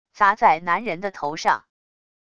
砸在男人的头上wav音频